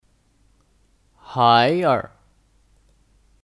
孩子 (Háir 孩儿)